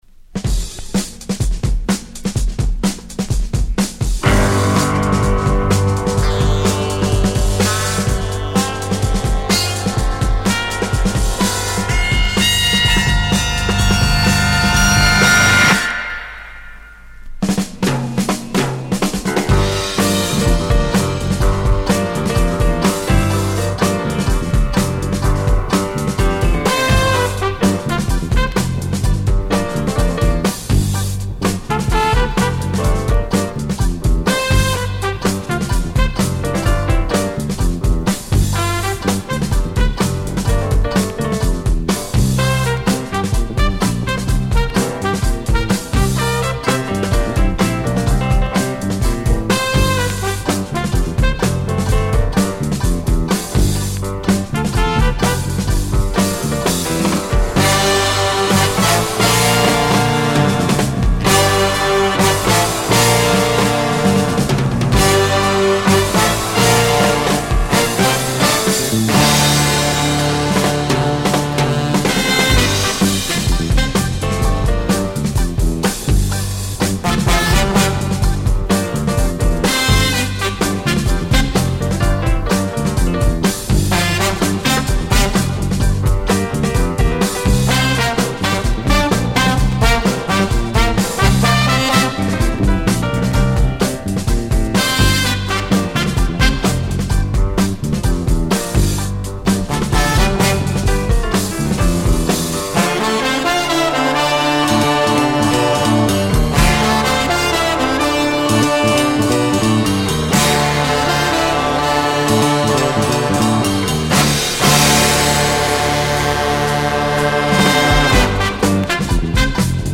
Jazz Groove germany
タイトなドラミングと重厚なブラスアンサンブルが素晴らしい傑作ジャズ・ファンク！